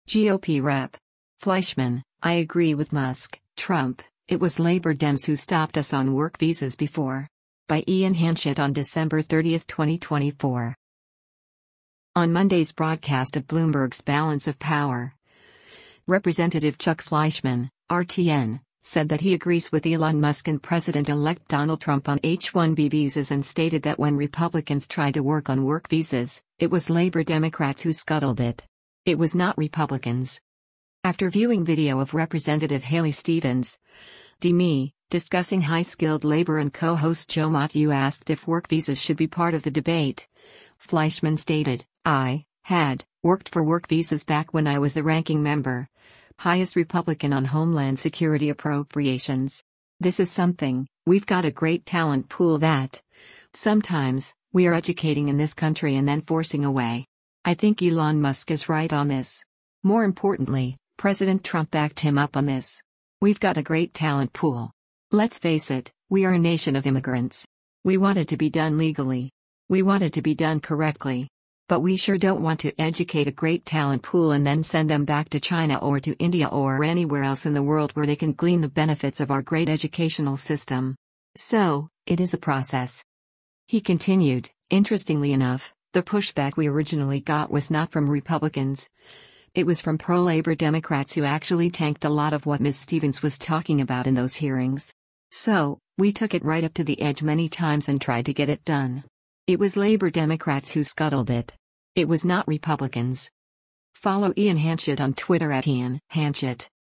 On Monday’s broadcast of Bloomberg’s “Balance of Power,” Rep. Chuck Fleischmann (R-TN) said that he agrees with Elon Musk and President-Elect Donald Trump on H-1B visas and stated that when Republicans tried to work on work visas, “It was labor Democrats who scuttled it. It was not Republicans.”